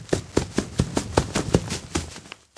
Running Footstep